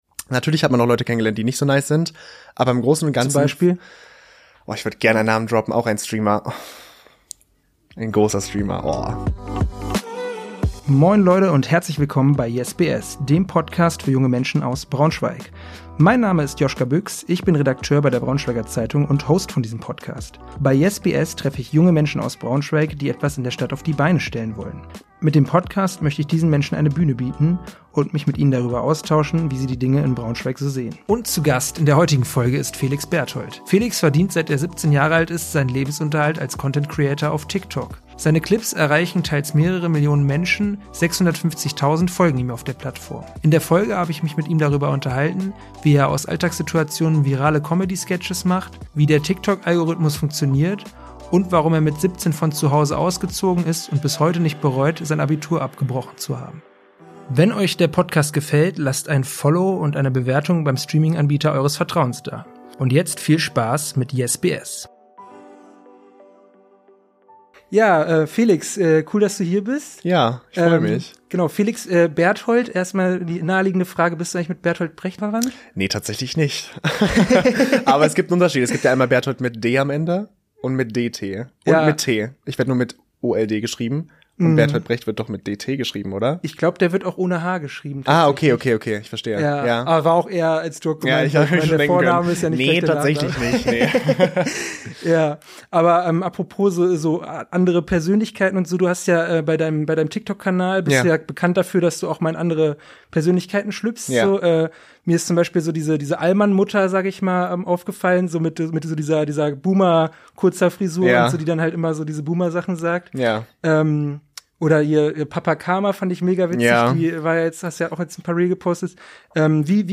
In der Folge habe ich mich mit ihm darüber unterhalten, wie seine Comedy-Sketche entstehen, wie der Algorithmus funktioniert und warum er es nicht bereut, sein Abi abgebrochen zu haben.